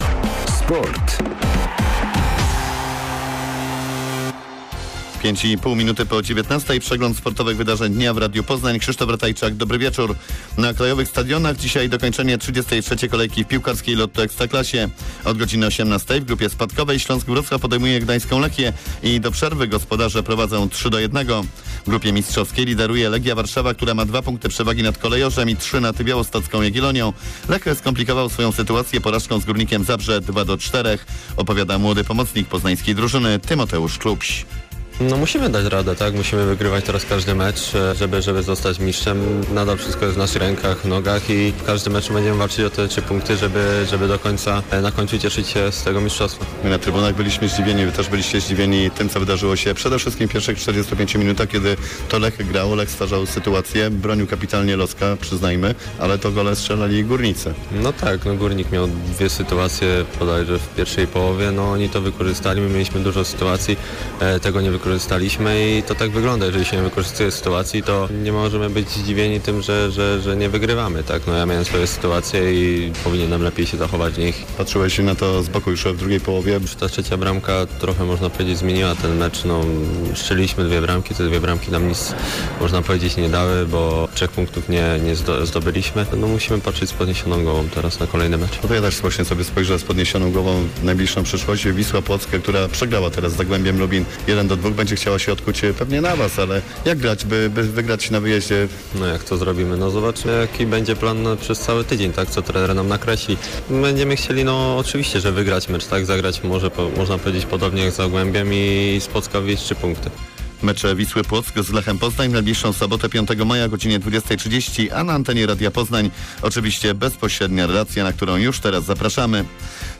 30.04 serwis sportowy godz. 19:05